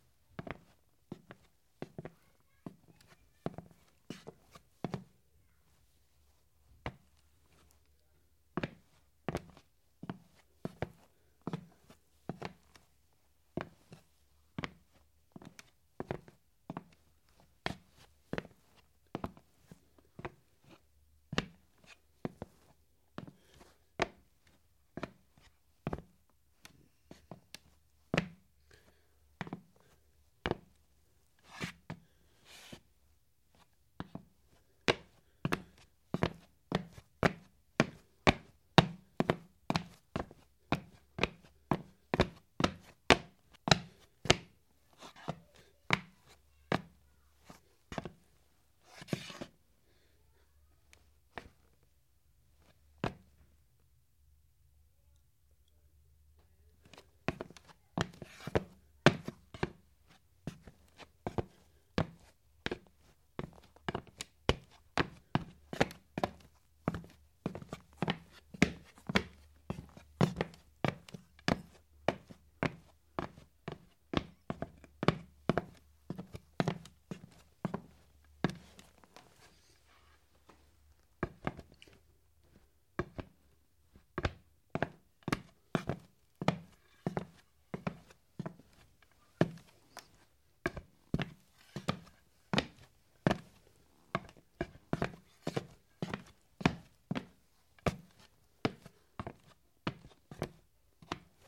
近身搏击 " 脚步声 女装平底鞋 硬木地板
描述：在硬木地板上的女人的礼服平底鞋的脚步foley。 这种声音是在受控条件下在一个foley工作室录制的。
用Blue Bluebird麦克风录制到Tascam DR40上。
Tag: 脚步 工作室 FX SFX soundeffects soundfx 声音 拟音